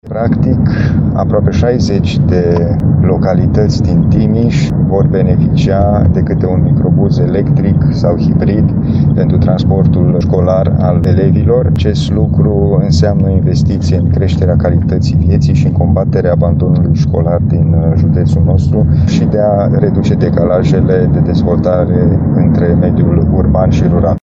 Potrivit președintelui CJ Timiș, Alin Nica, acesta este al doilea proiect pentru achiziția de microbuze școlare hibrid.